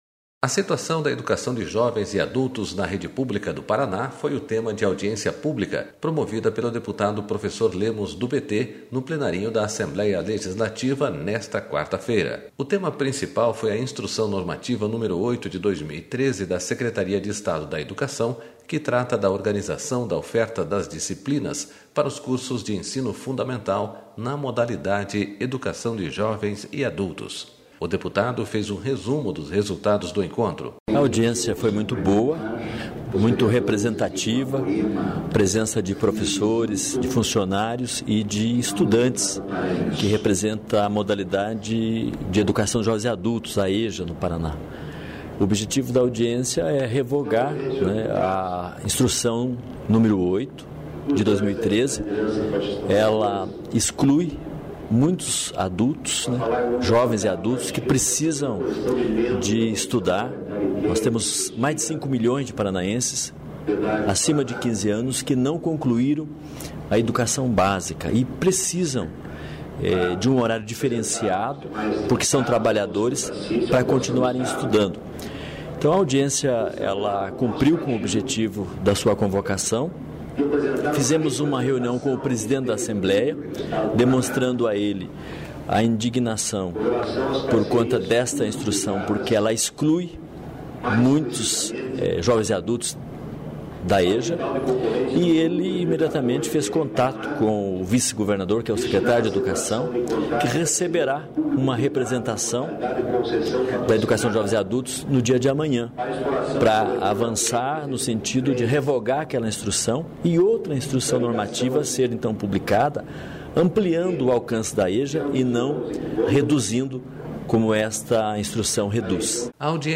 Audiência pública discute Educação de Jovens e Adultos no Paraná